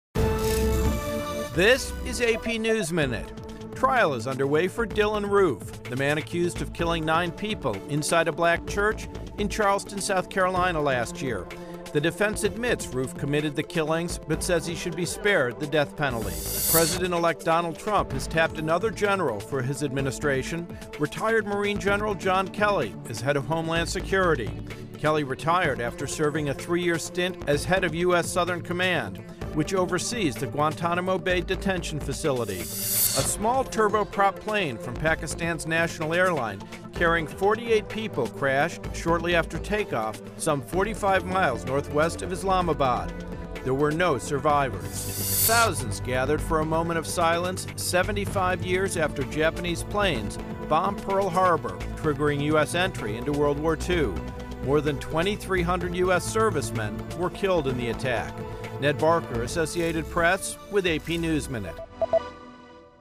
英语资讯
美语听力练习素材:纪念日军偷袭珍珠港75周年